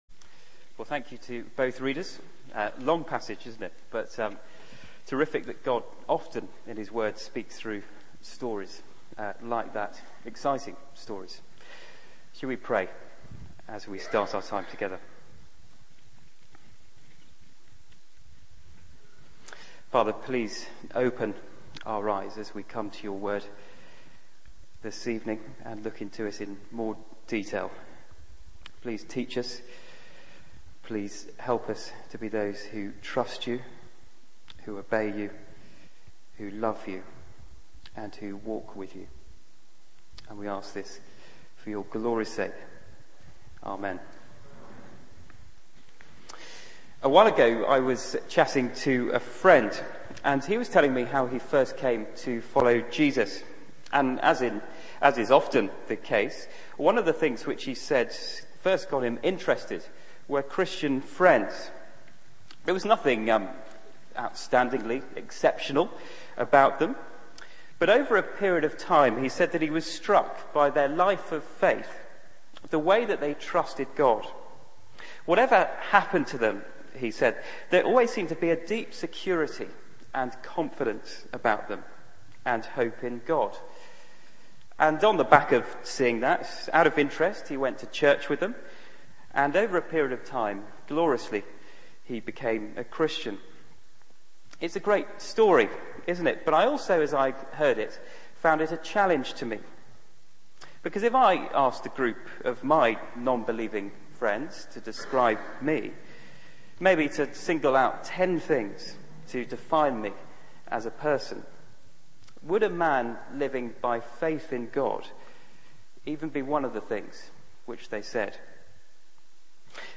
Media for 6:30pm Service on Sun 28th Nov 2010 18:30 Speaker
Passage: Genesis 23-24 Series: The Gospel according to Abraham Theme: A death and a wedding Sermon